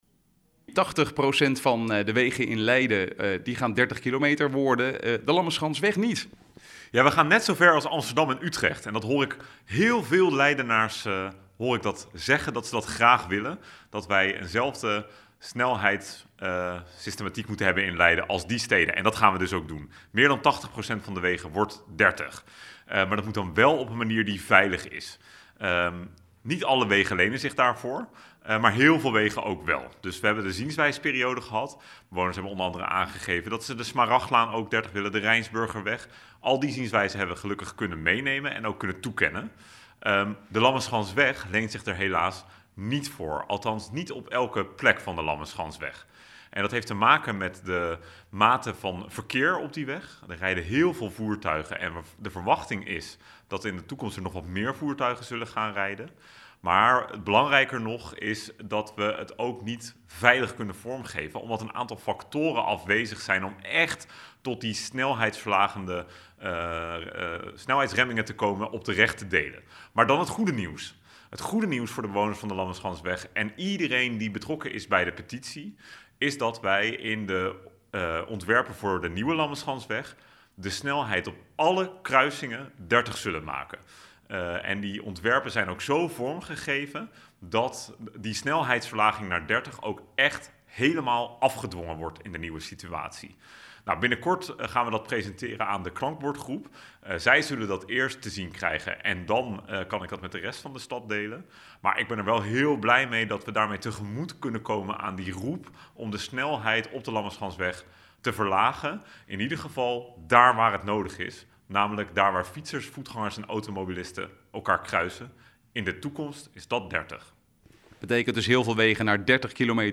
Verkeerswethouder Ashley North over het verlagen van de snelheid op wegen in Leiden.